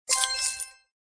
catch.mp3